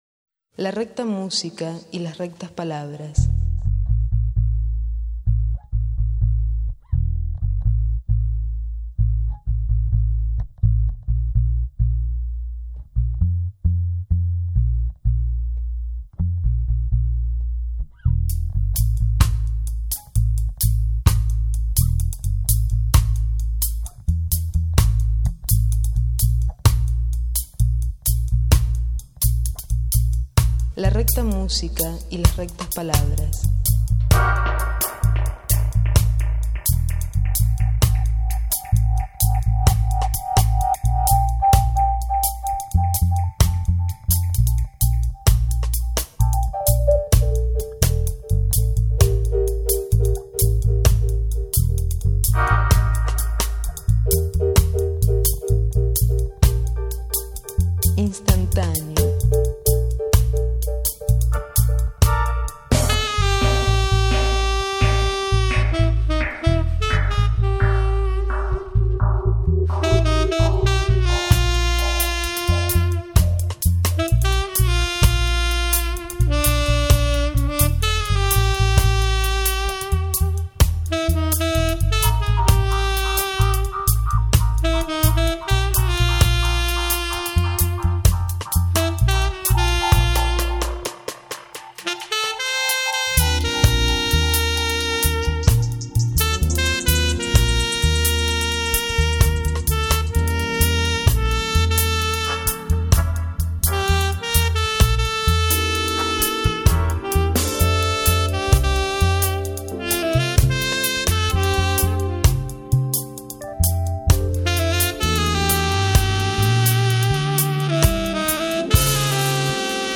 sax tenore
Tastiere
Basso
Batteria